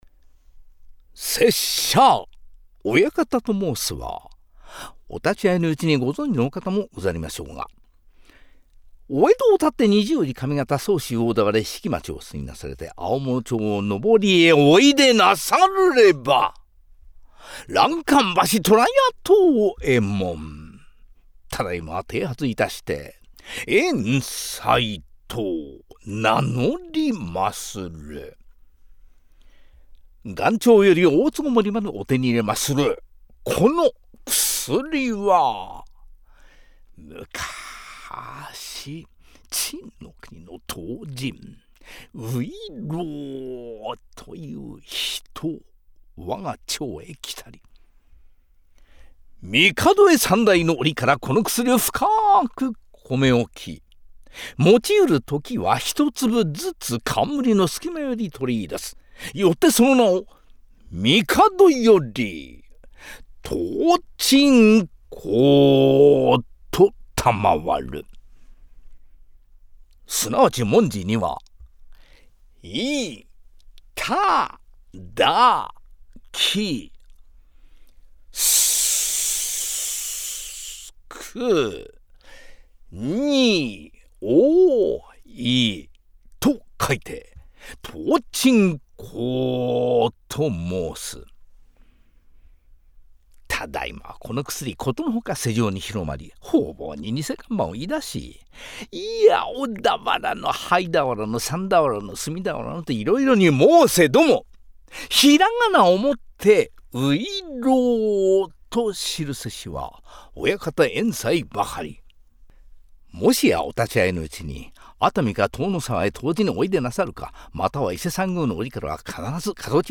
音声サンプル